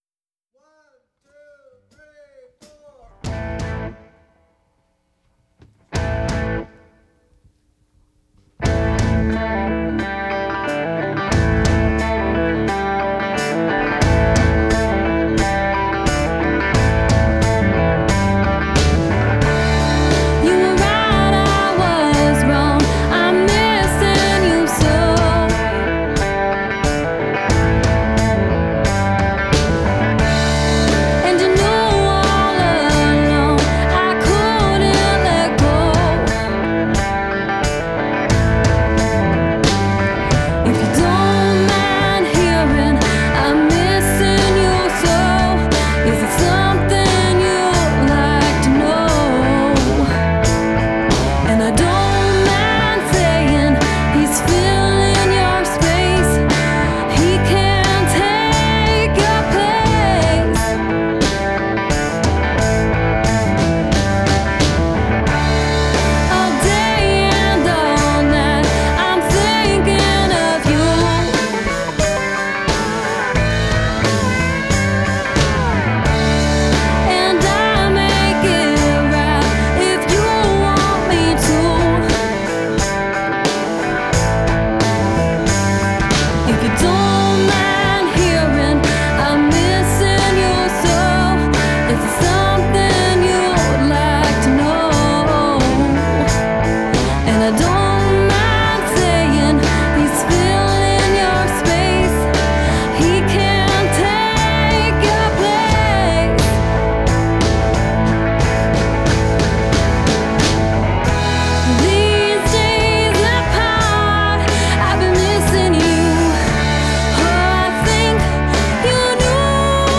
An original Recorded at Third Ward Records February 2015.